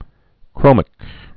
(krōmĭk)